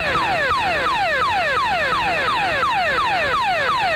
SOS_Beacon.ogg